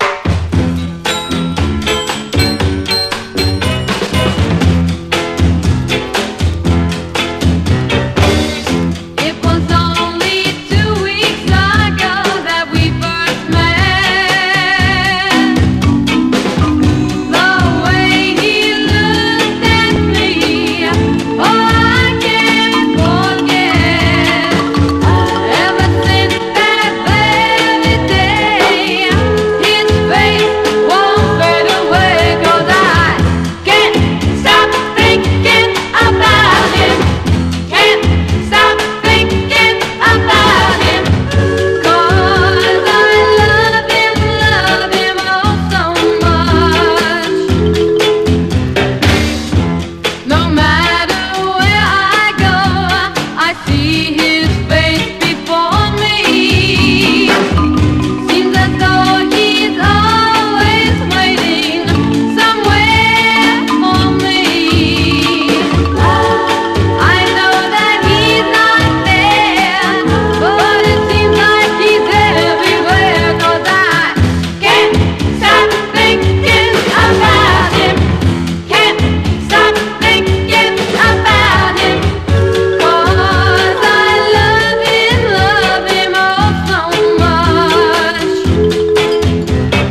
アッパーなビートに手拍子、煌くベルの音が言い得ぬ幸福感。